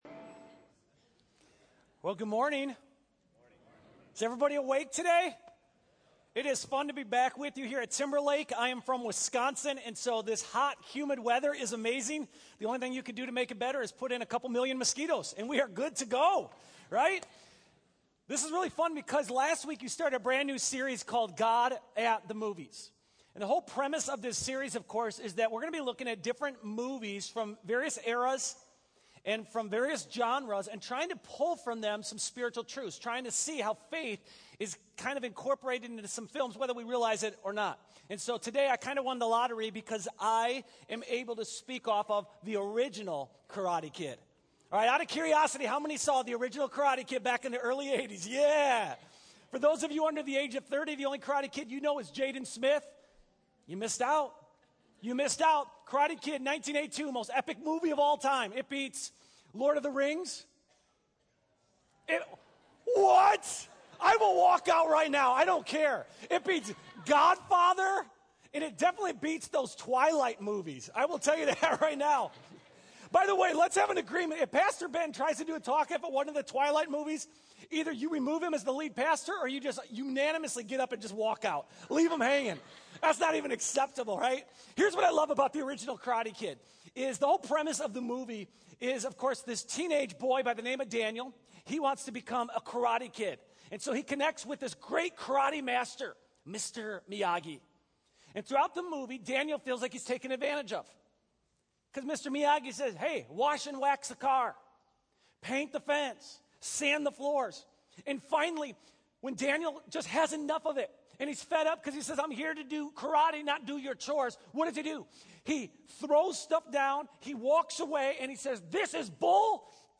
Guest Pastor